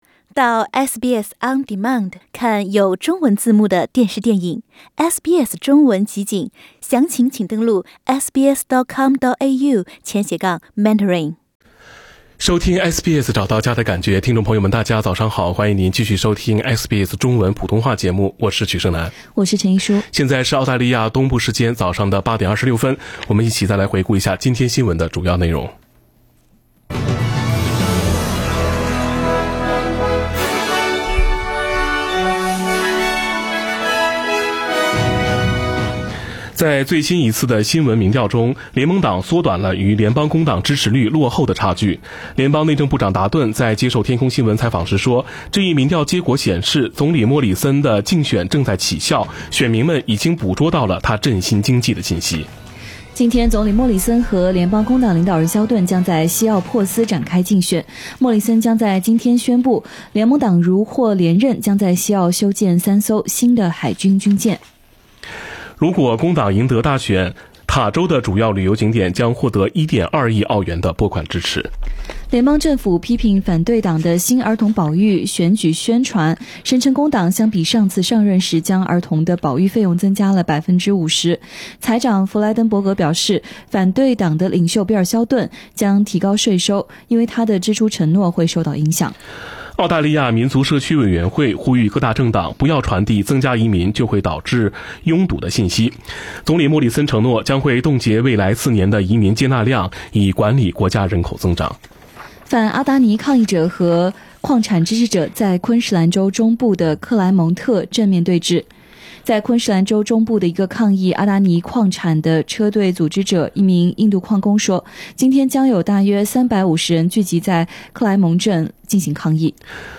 SBS早新闻（4月29日）